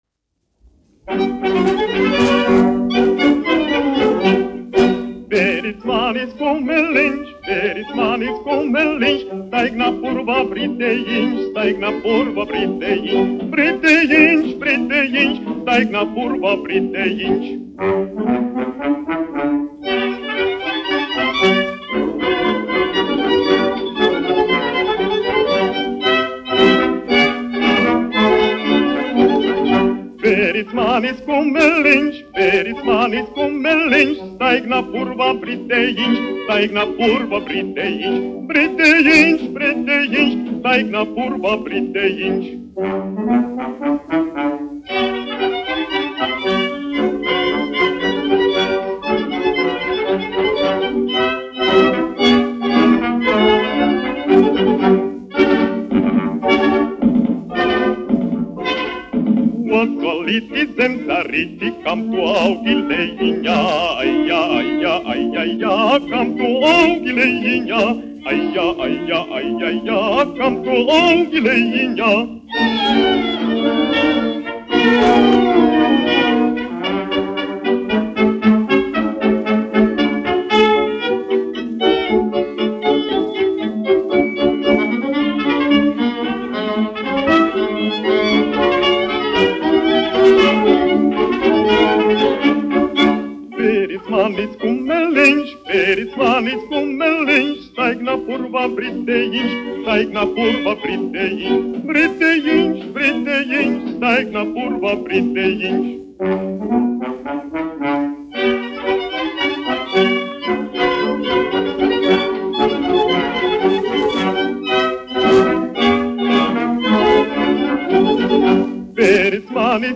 1 skpl. : analogs, 78 apgr/min, mono ; 25 cm
Fokstroti
Latviešu tautasdziesmas
Popuriji
Latvijas vēsturiskie šellaka skaņuplašu ieraksti (Kolekcija)